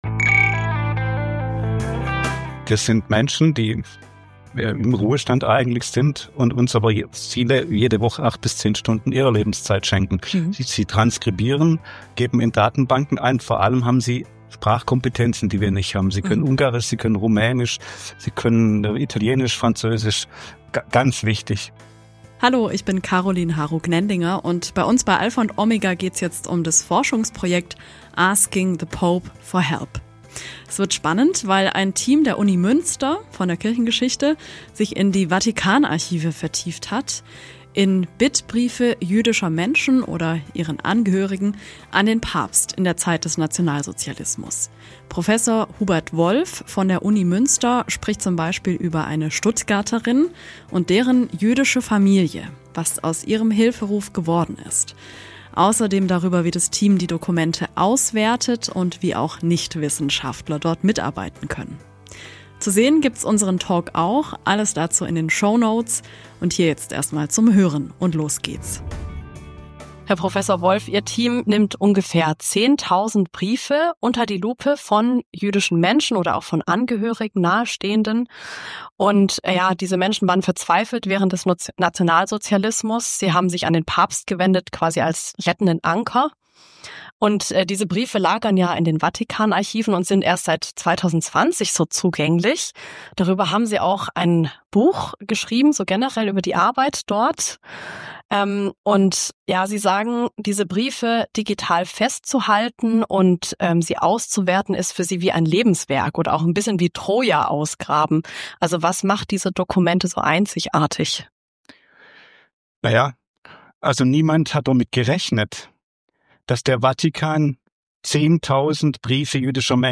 In dieser Episode spricht Professor Hubert Wolf von der Uni Münster über das Forschungsprojekt "Asking the Pope for help" und die Vatikanarchive.